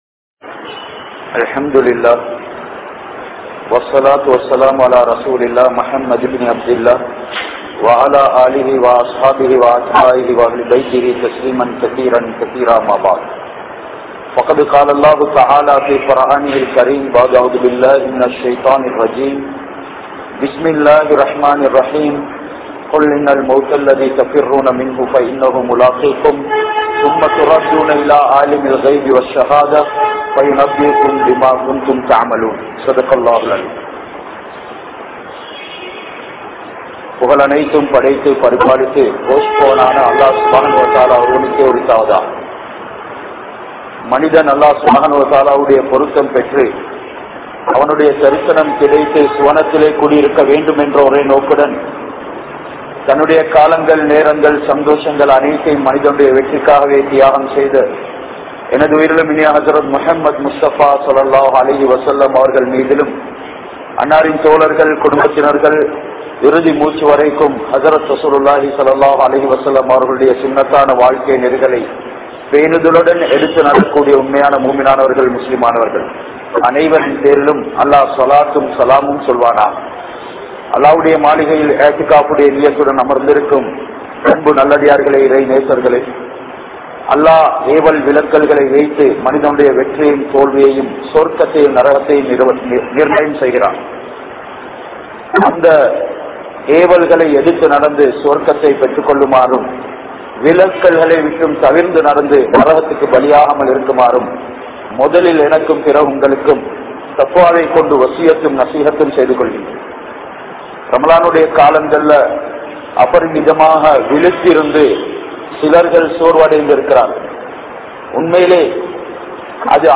Maranam | Audio Bayans | All Ceylon Muslim Youth Community | Addalaichenai
Kandy,Malay Jumua Masjith